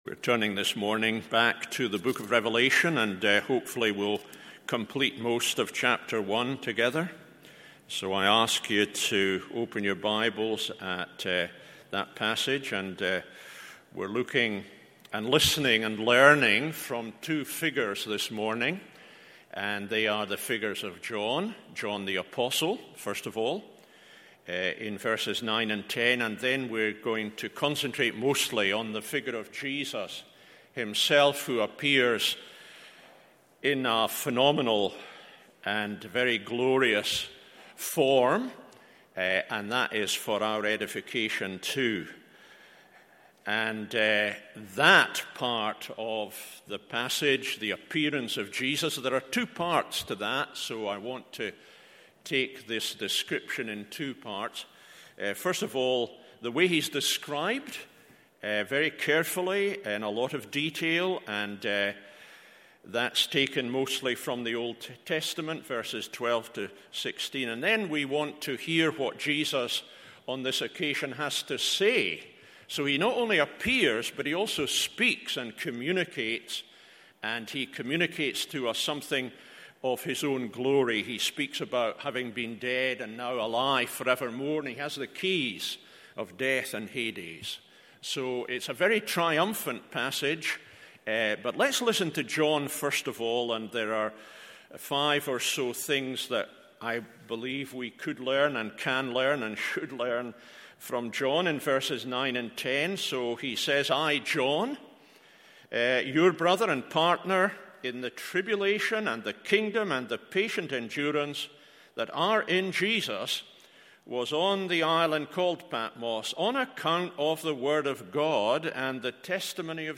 MORNING SERVICE Revelation 1:9-20…